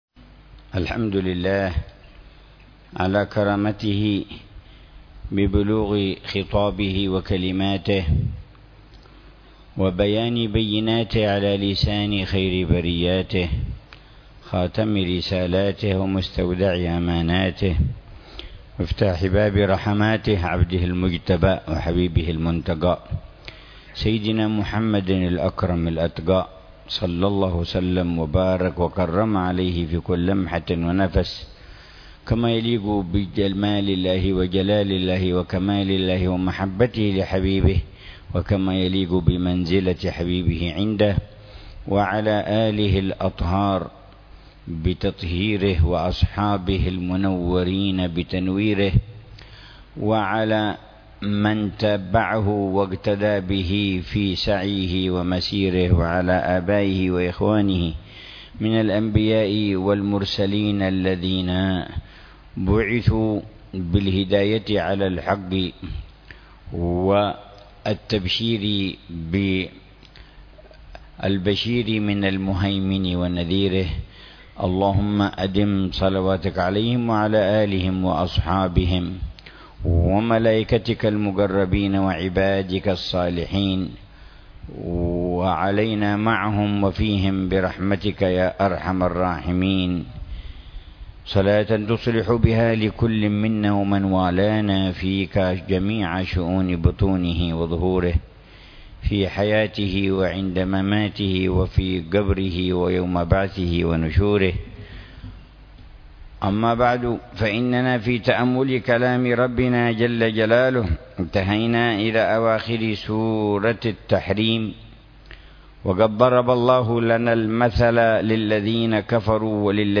تفسير الحبيب العلامة عمر بن محمد بن حفيظ للآيات الكريمة من سورة التحريم، ضمن الدروس الصباحية لشهر رمضان المبارك لعام 1441، من قوله تعالى: